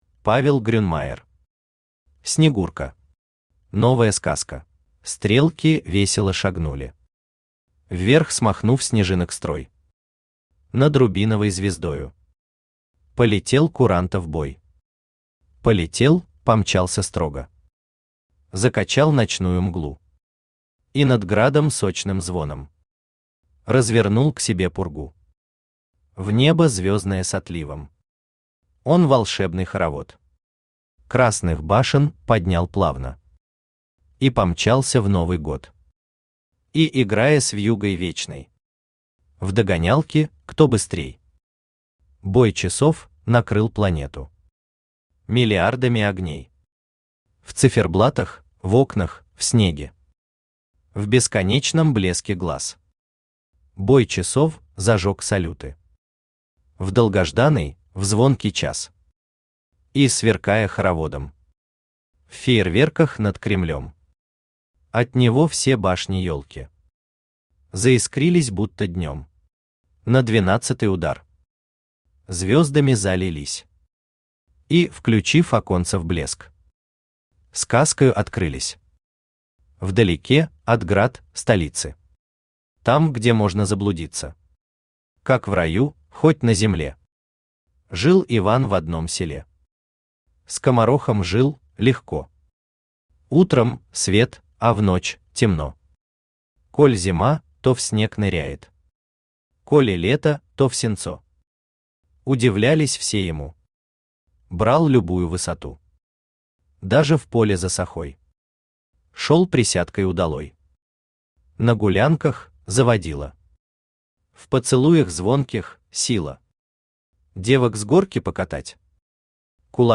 Новая сказка Автор Павел Грюнмайер Читает аудиокнигу Авточтец ЛитРес.